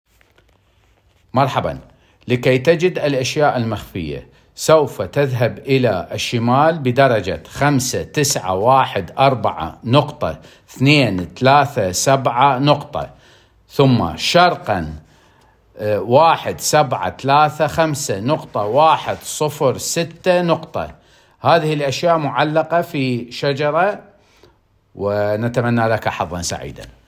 I södertälje finns många olika nationaliteter och språk. Här kommer en hälsning från en Södertäljebo!